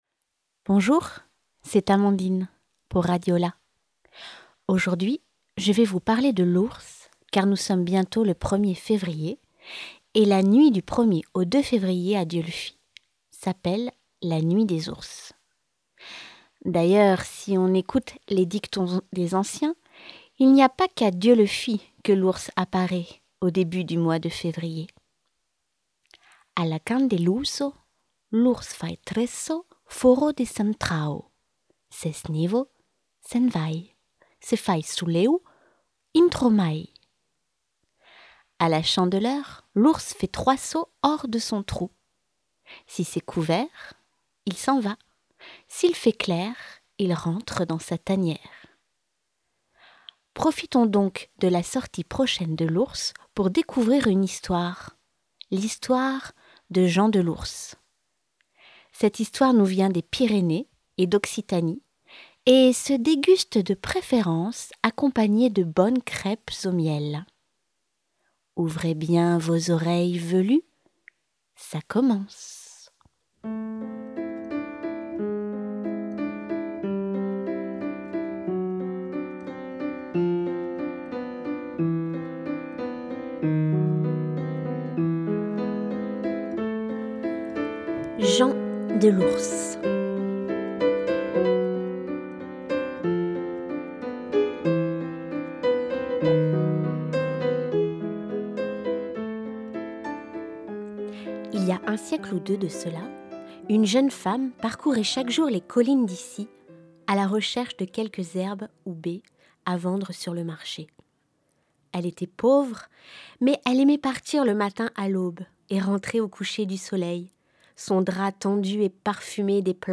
Un conte raconté par